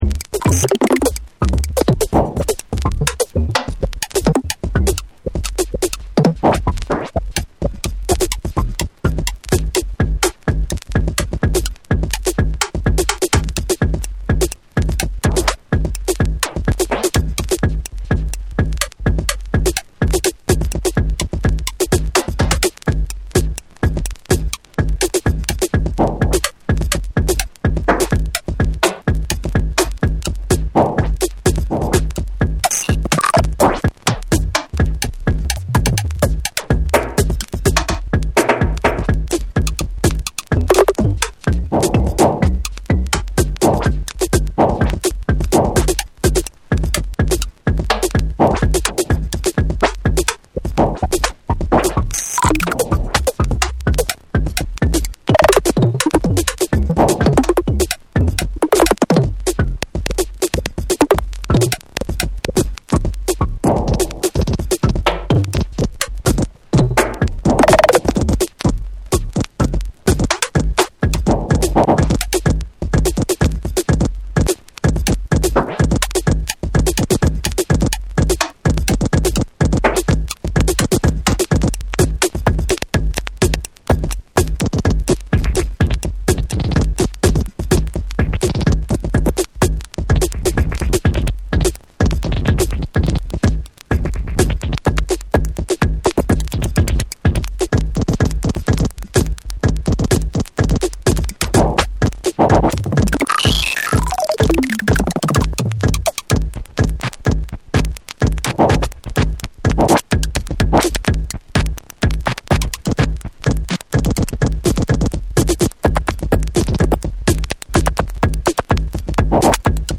硬質ながらもどこか内省的なムードを湛えた、摩訶不思議でユーモラスな雰囲気も伺えるトラックを収録。
JAPANESE / BREAKBEATS